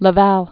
(lə-văl, lä-väl)